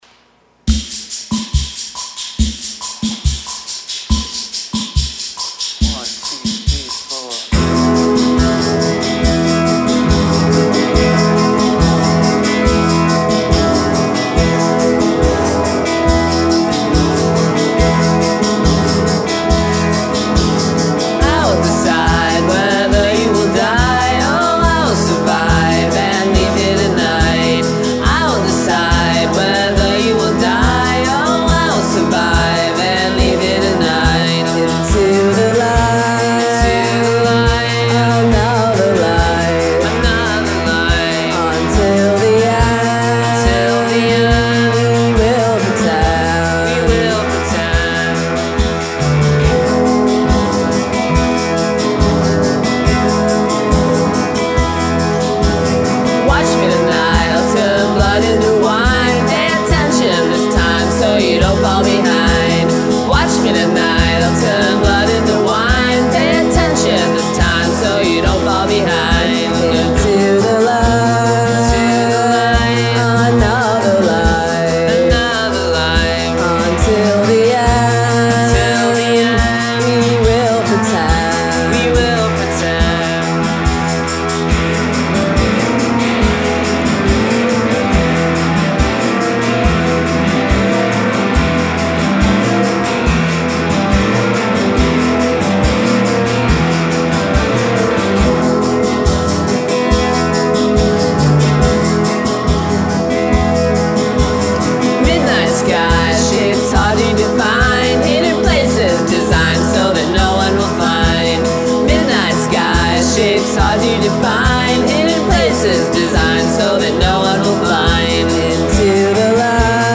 pop duo
keys and drum machine